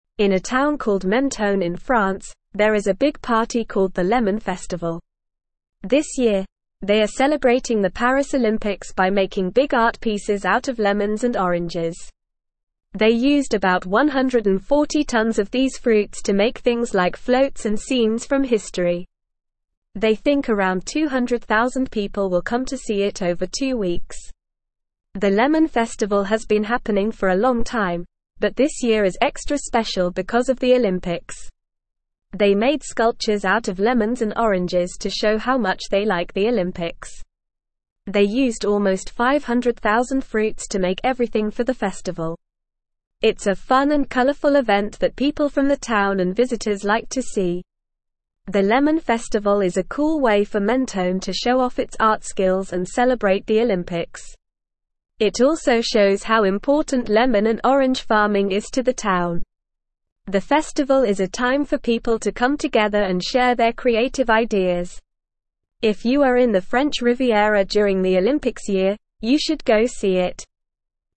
Normal
English-Newsroom-Lower-Intermediate-NORMAL-Reading-Big-Lemon-Festival-Celebrates-Paris-Olympics-with-Fruit-Art.mp3